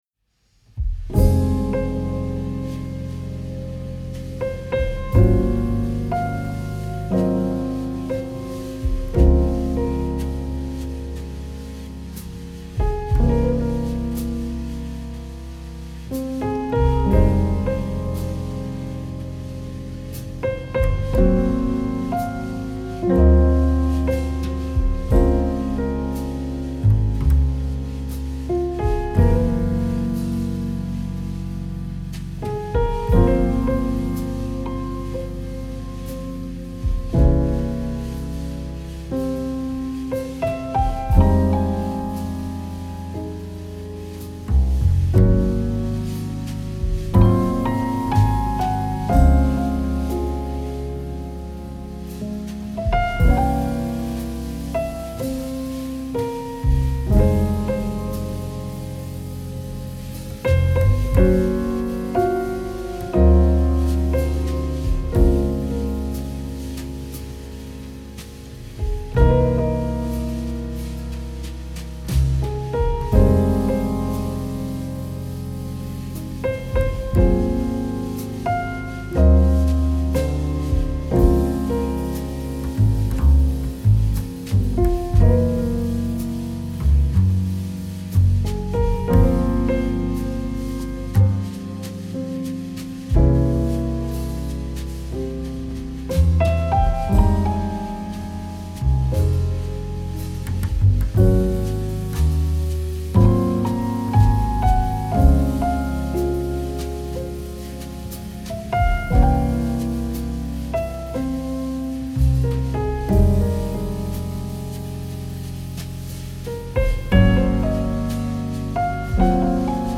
آرامش بخش , پیانو , جاز موزیک